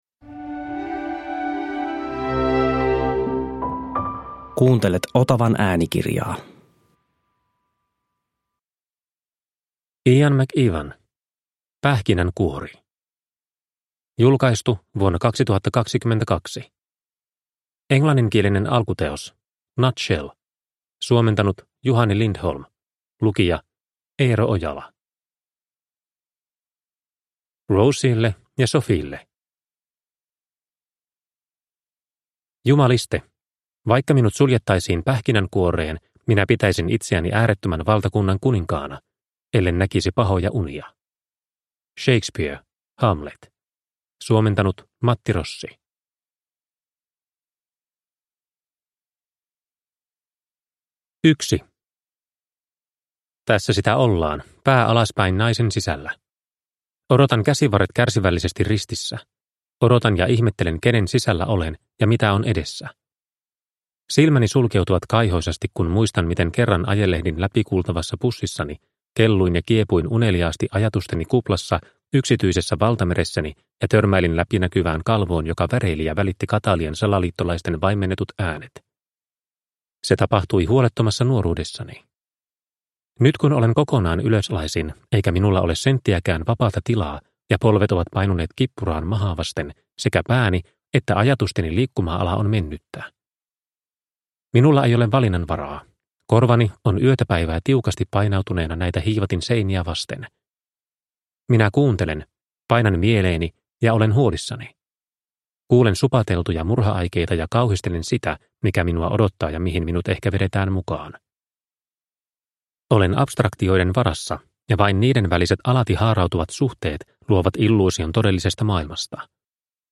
Pähkinänkuori – Ljudbok – Laddas ner